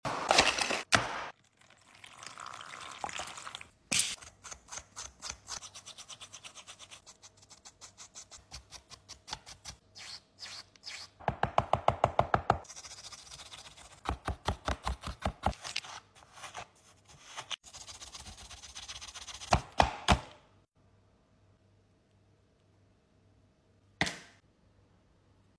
ASMR Underwater Diamond Mining!! sound effects free download